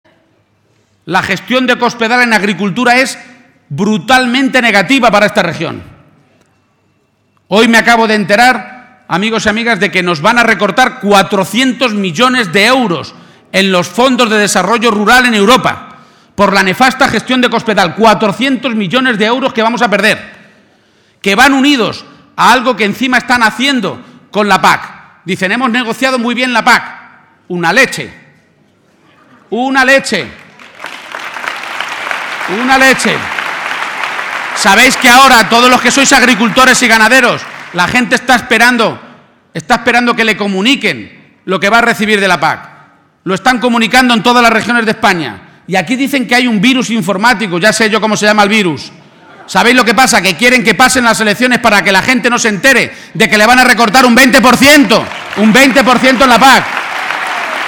Audio Page-acto Albacete 1